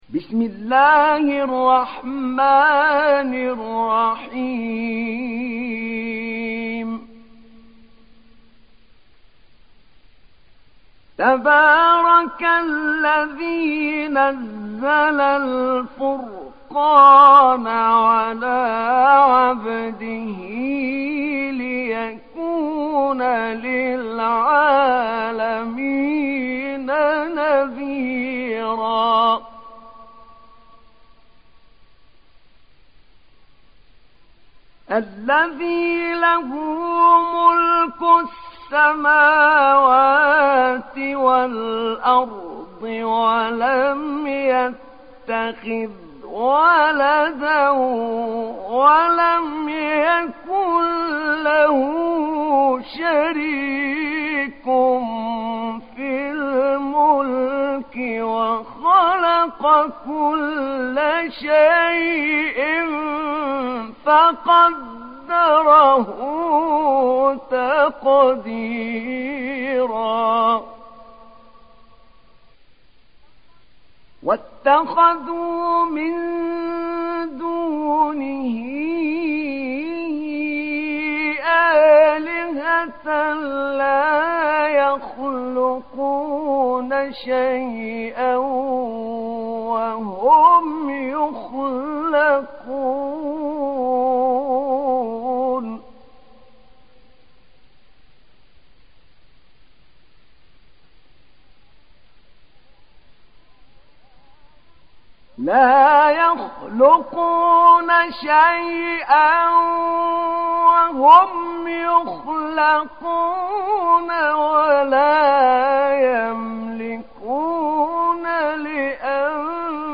Sourate Al Furqan Télécharger mp3 Ahmed Naina Riwayat Hafs an Assim, Téléchargez le Coran et écoutez les liens directs complets mp3